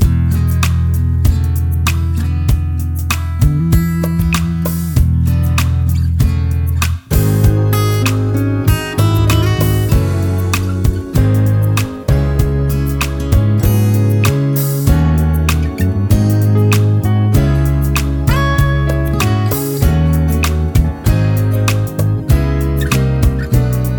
no Backing Vocals Soft Rock 3:52 Buy £1.50